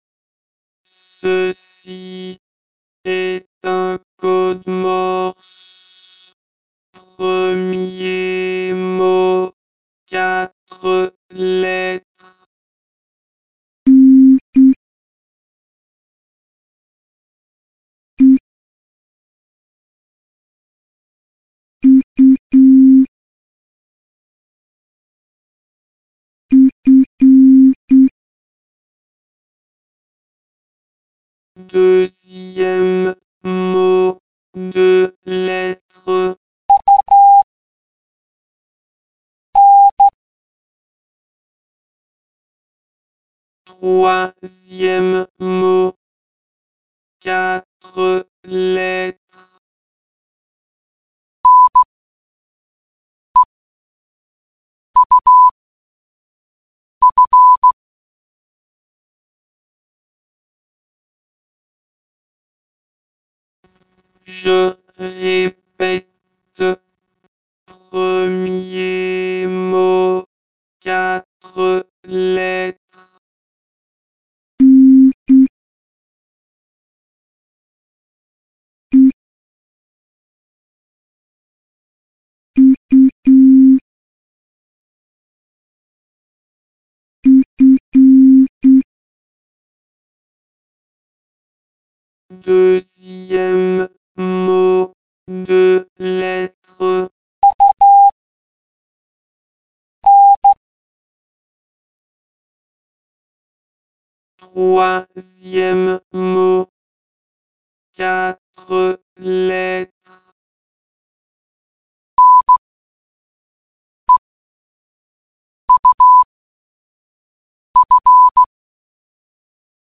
Ta prochaine énigme est de déchiffrer un code morse.
escape game code morse.mp3